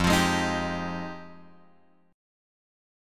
F Minor 9th